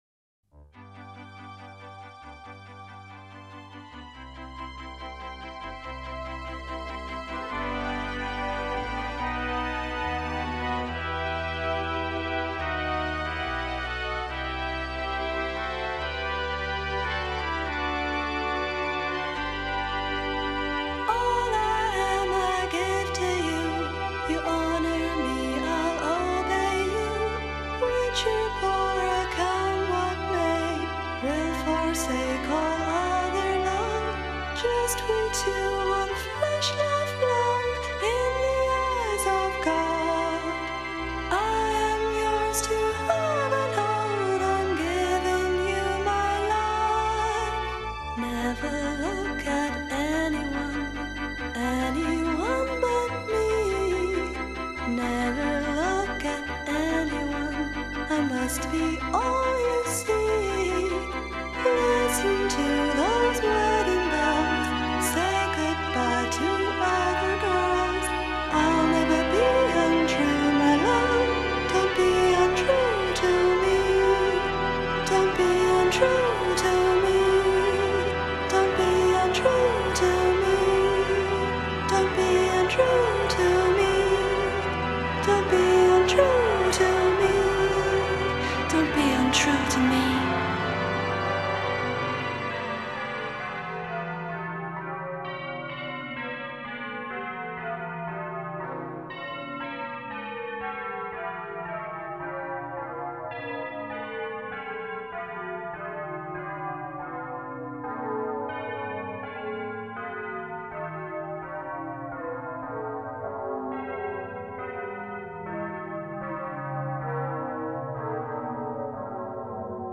Full songs in stereo